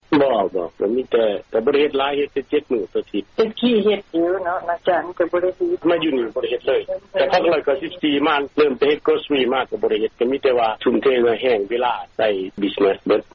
ທີ່ທ່ານຫາກໍໄດ້ຮັບຟັງຜ່ານໄປນັ້ນ ແມ່ນການໂອ້ລົມກັບລາວອາເມຣິກັນຄອບຄົວນຶ່ງ ໃນເຂດເມືອງໂທເລໂດ ລັດໂອຮາຍໂອ.